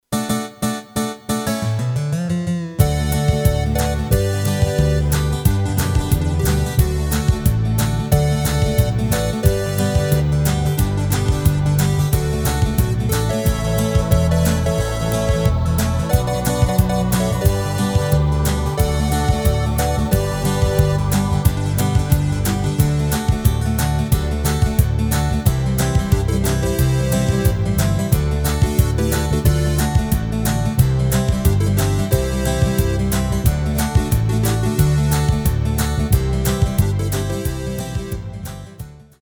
Rubrika: Folk, Country